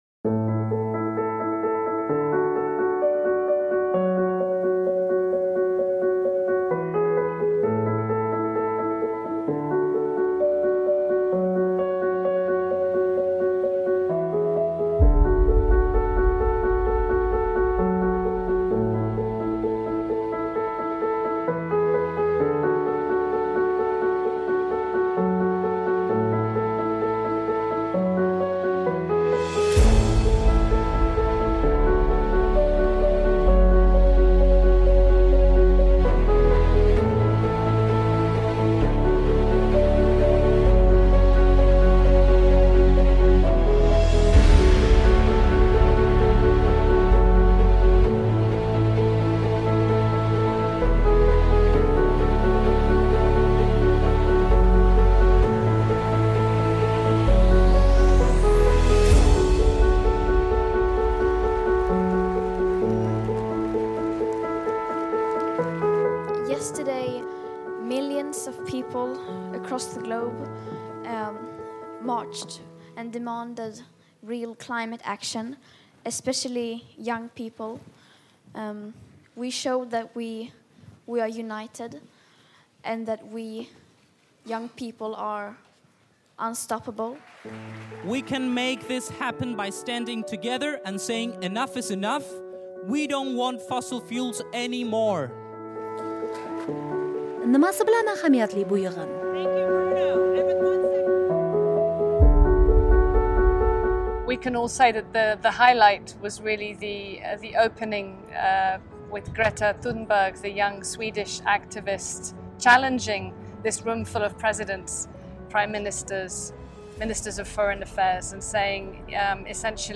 Birlashgan Millatlar Tashkilotining O'zbekistondagi bosh vakili Helena Freyzer "Amerika Ovozi" bilan Nyu-Yorkda Bosh Assambleyaning yillik sessiyasi o'tayotgan paytda suhbatlashar ekan, bugungi prioritetlarga birma-bir to'xtaldi.
Islohotlar: BMTning O'zbekistondagi bosh vakili bilan suhbat